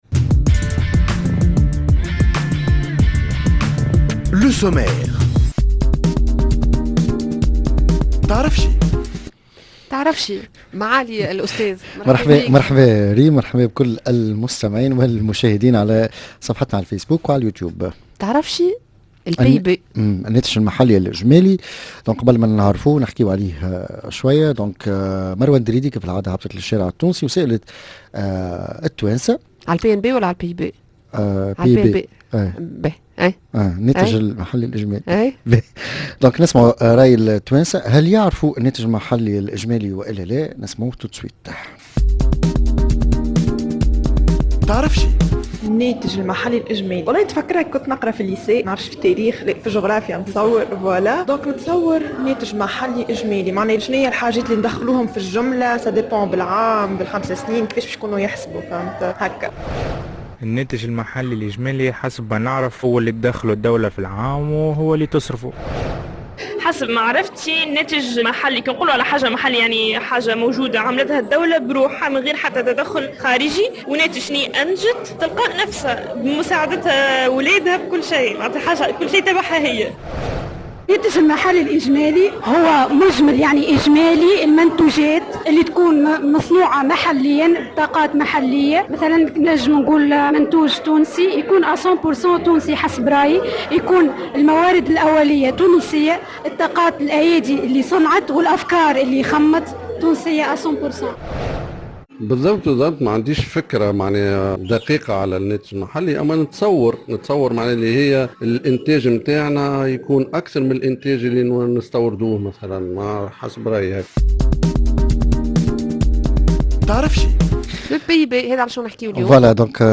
micro troittoir